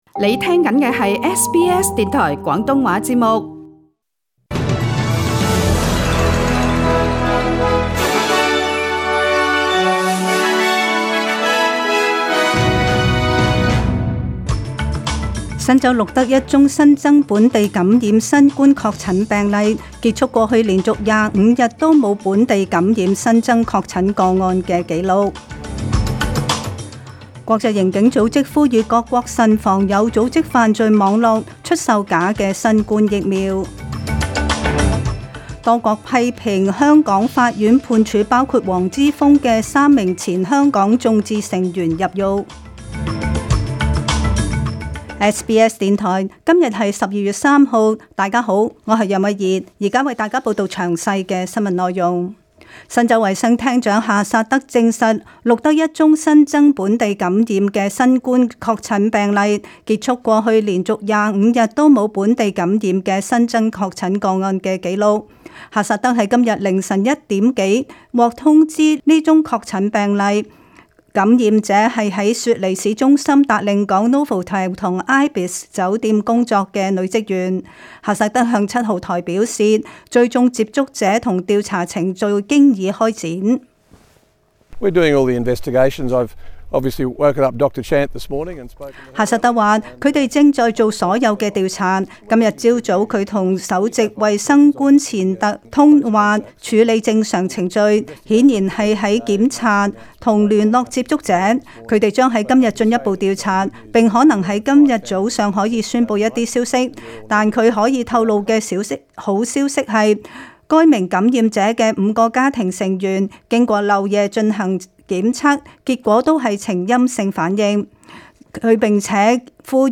SBS 中文新聞 （十二月三日）